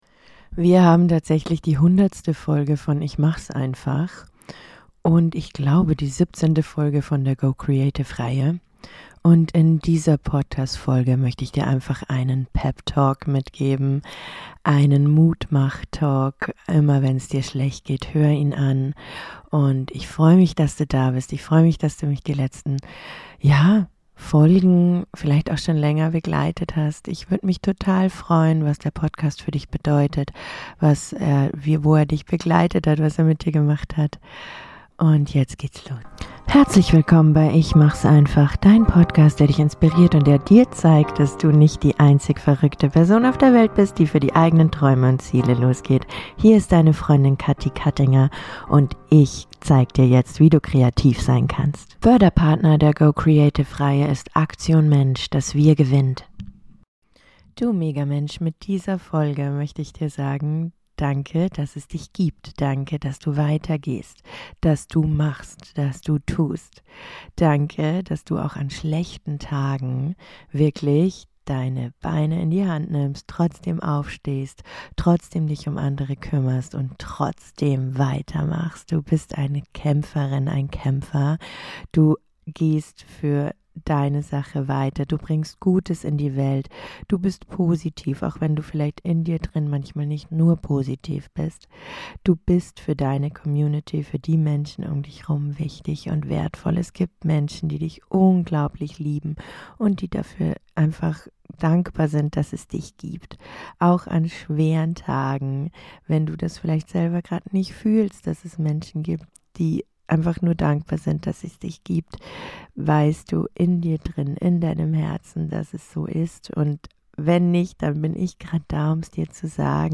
In dieser besonderen Jubiläumsfolge schenke ich dir einen kraftvollen Pep Talk, vollgepackt mit positiver Energie, Ermutigung und ganz viel Herz.